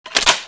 lmg_clipin.wav